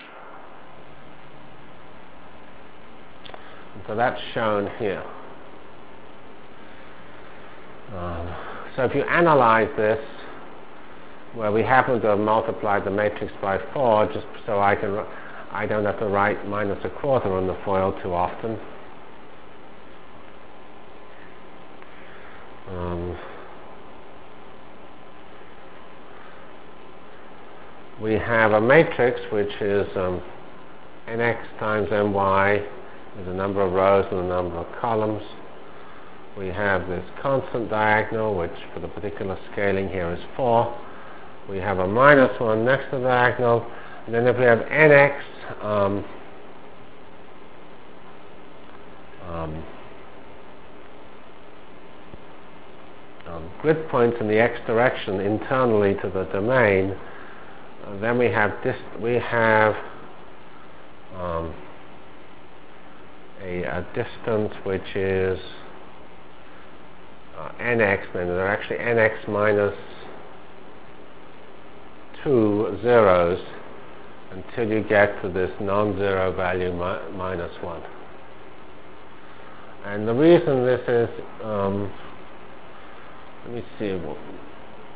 Delivered Lectures of CPS615 Basic Simulation Track for Computational Science -- 8 November 96.